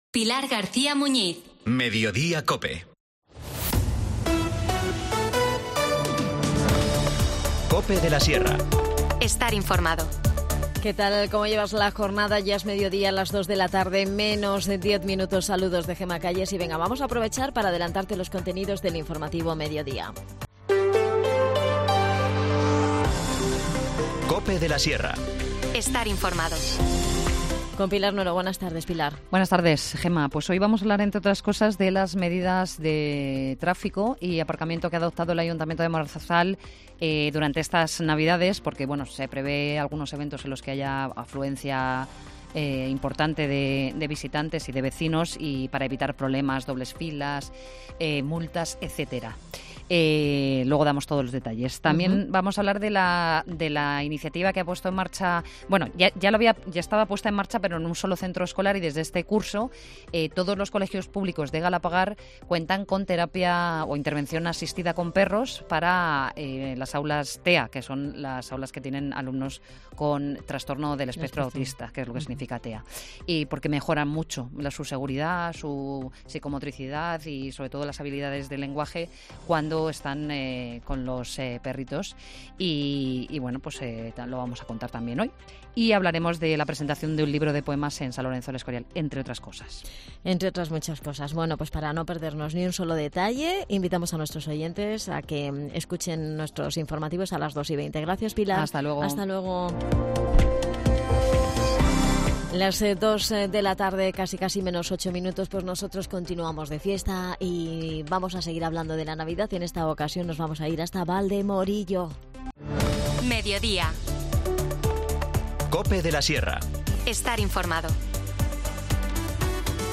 Nos adelanta todos los detalles Eva Ruiz, concejal de Deportes, Juventud, Comercio y Promoción Económica en el Ayuntamiento de Valdemorillo.